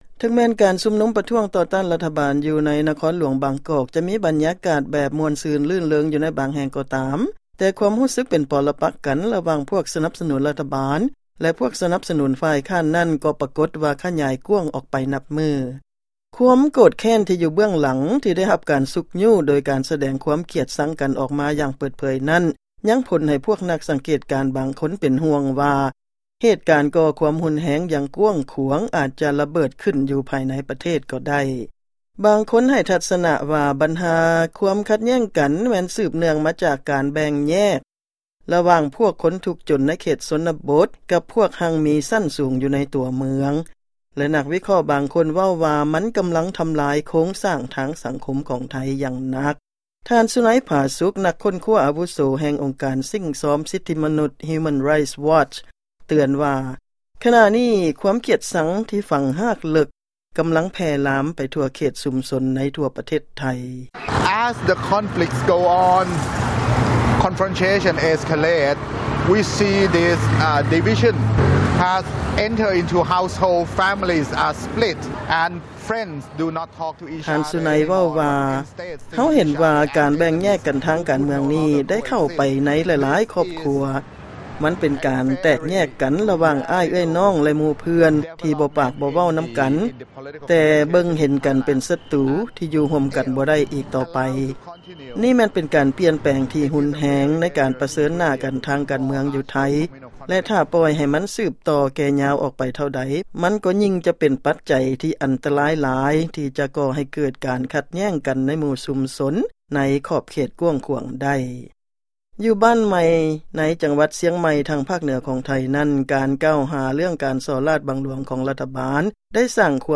ຟັງລາຍງານກ່ຽວກັບ ຜົນກະທົບຂອງ ວິກິດການການເມືອງໃນໄທ ຕໍ່ຄອບຄົວ